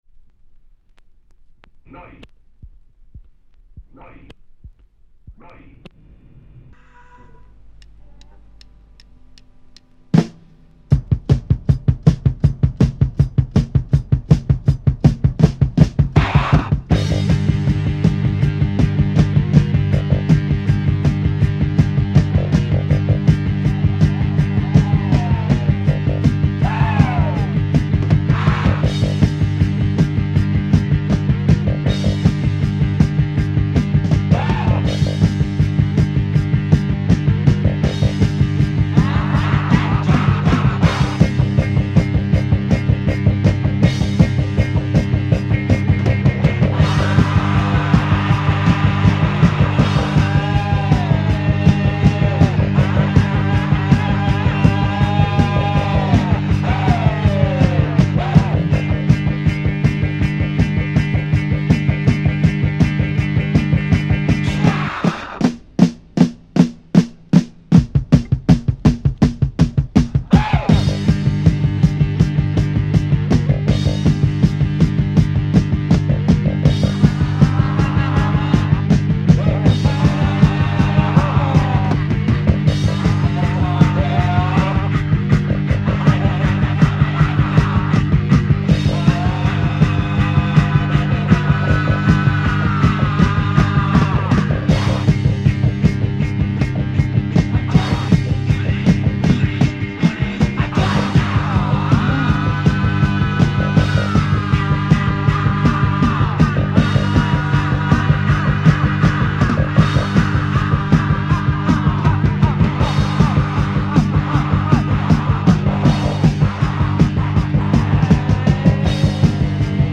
Monster German Kraut Punk psych 2Siders!
Moster Kraut Cosmic Psych punk two siders!